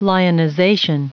Prononciation du mot : lionization
lionization.wav